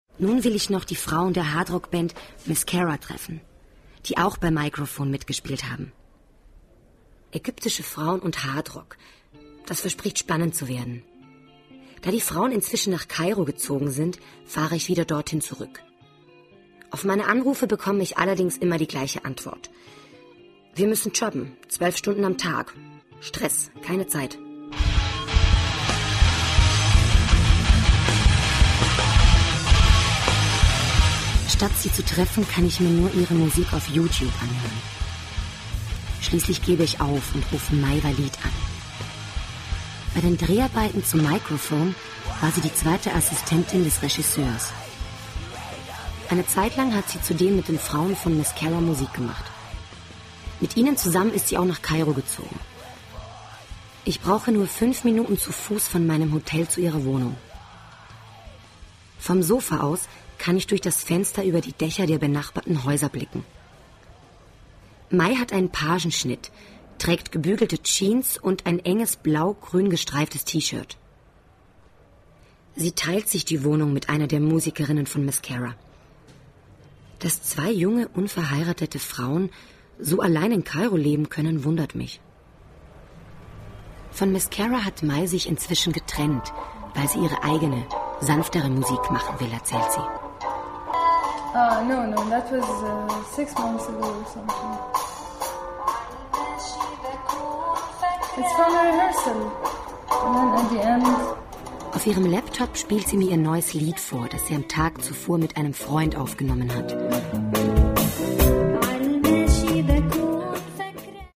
Sprecherin, Werbesprecherin